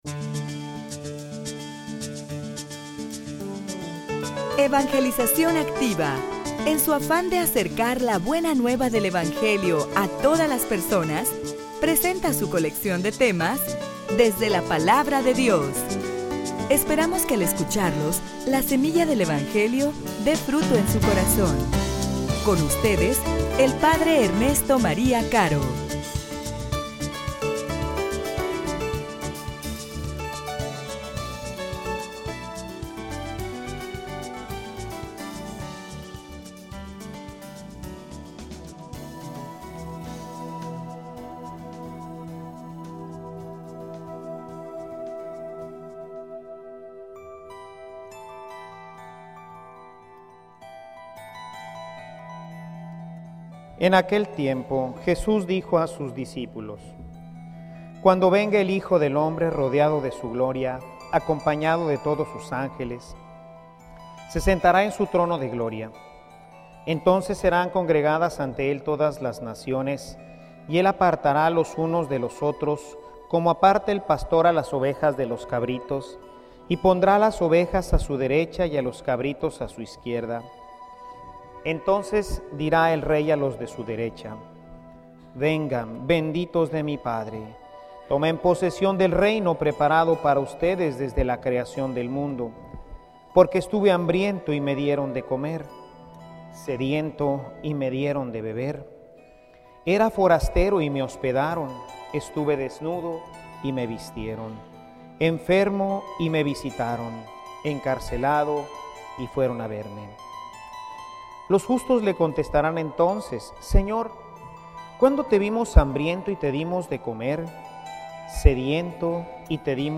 homilia_Mi_Rey_mi_Senor.mp3